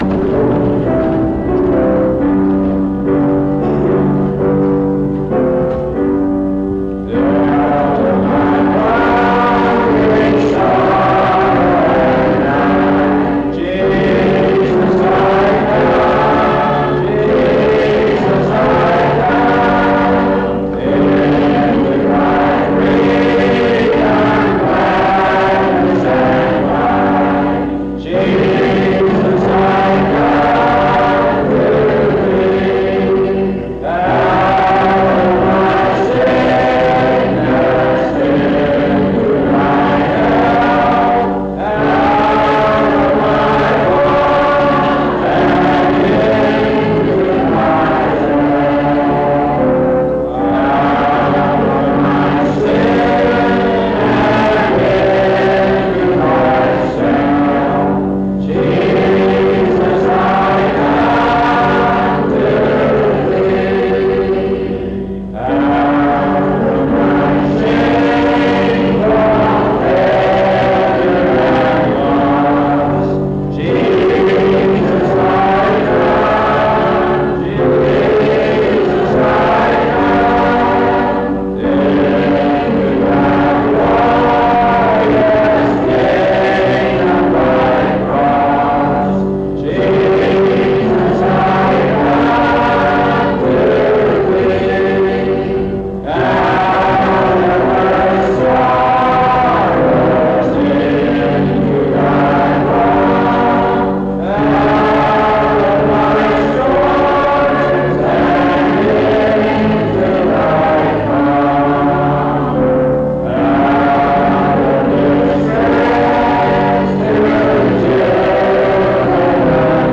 Jesus I Come Item 9f55590a89c5973f379f11656f8495d6c8baa0a5.mp3 Title Jesus I Come Creator Congregation Description This recording is from the Calvary Methodist Church Revival.